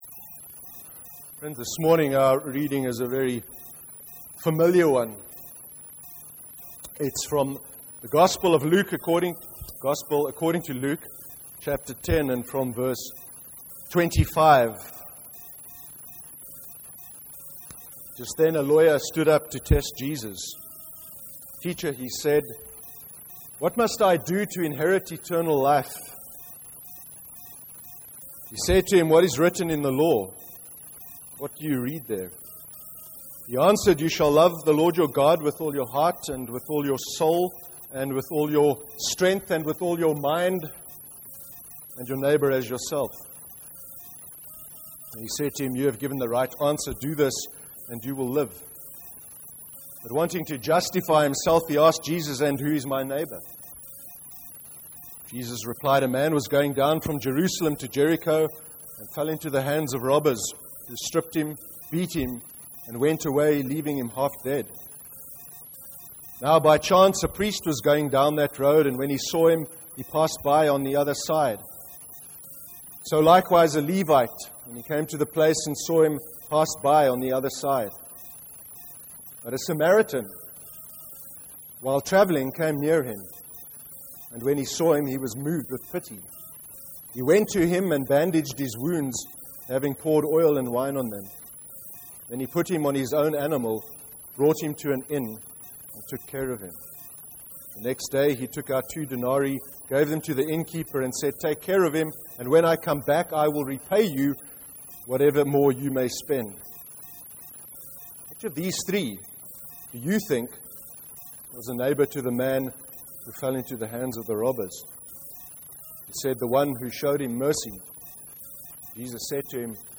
31/08/2014 sermon: Forgiveness, and the new humanity in Jesus Christ (Luke 10:25-37 and Acts 8:26-34)